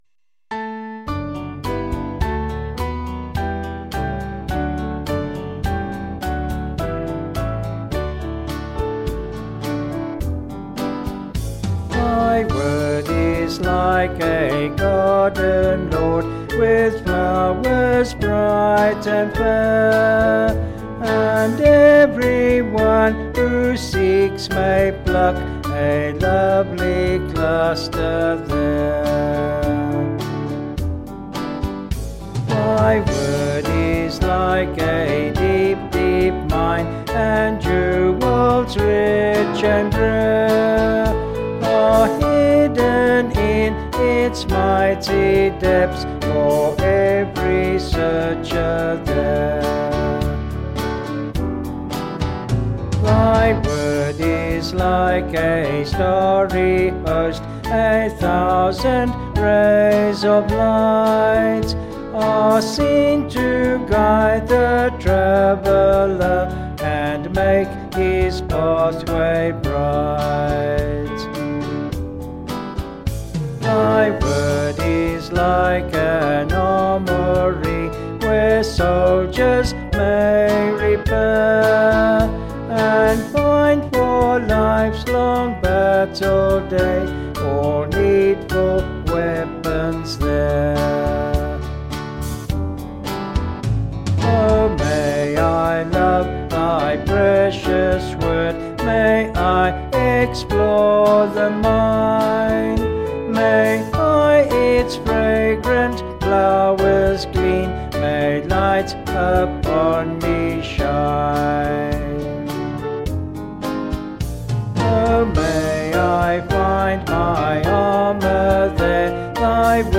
Vocals and Band   264kb Sung Lyrics